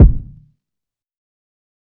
HFMKick10.wav